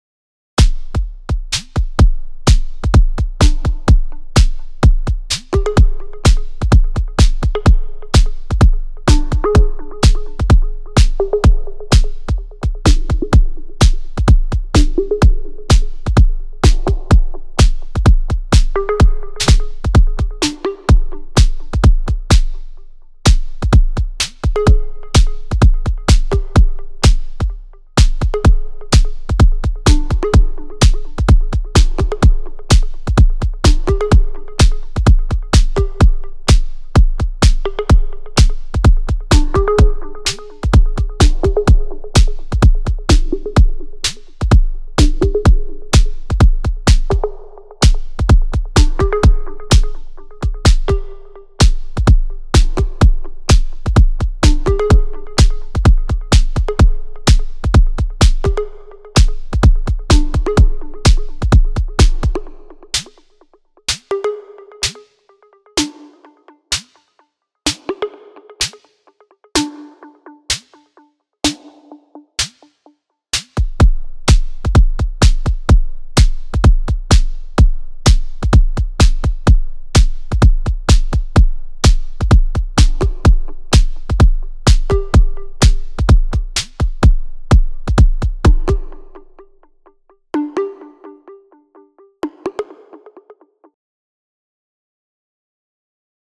I have had a go at it…surprised at what I got out of it to be fair :see_no_evil::joy: But just realised the sound I selected wasn’t in the list at the start of this thread:rofl: I used SD Classic so Ive posted the results here. 3 X SD classics, nothing fancy going on, but I am happy that I can actually get close to creating sounds that I was going for…the kick and pluck on 9 & 11 I had no idea that I could get these out of a snare for example and a standard snare on 10
using overbridge and not set up for the FX track so reverb and delay from stock ableton
Nice meaty sound to the drums :+1: